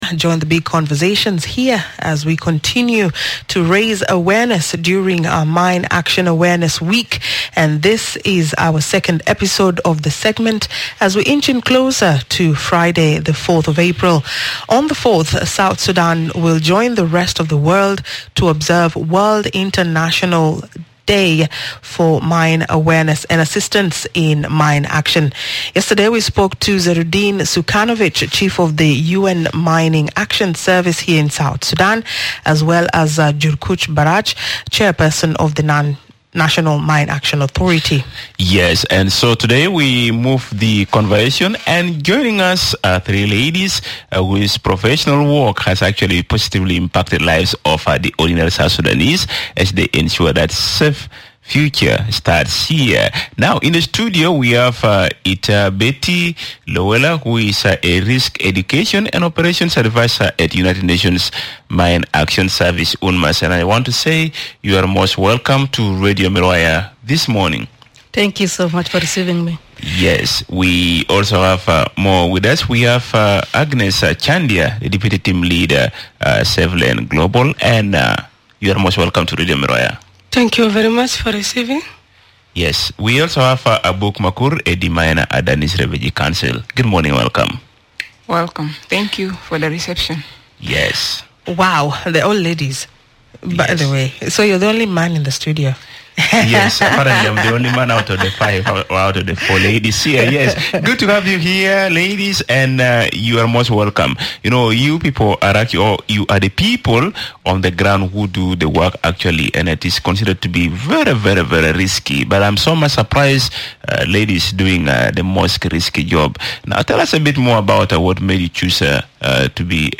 The Miraya Breakfast Show is in conversation with: